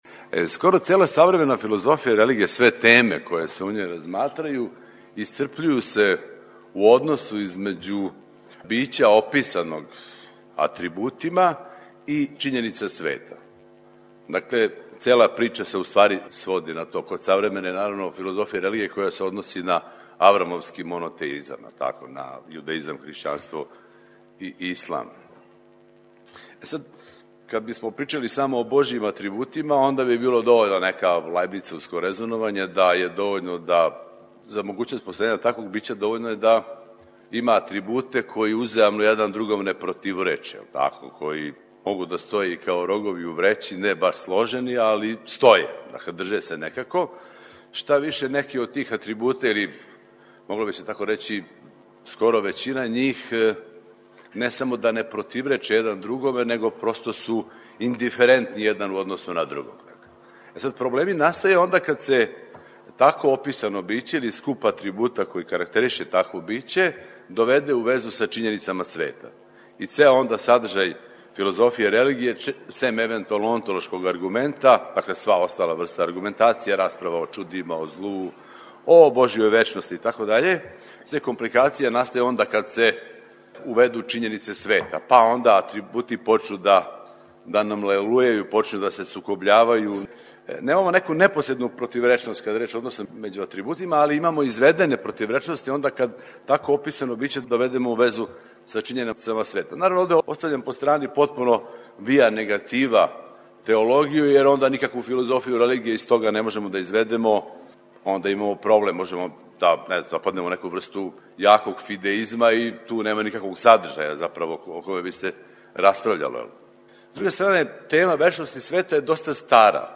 У циклусу 'Научни скупови', који емитујемо четвртком, можете пратити излагања и расправе са 17. Филозофске школе 'Felix Romuliana' у Зајечару.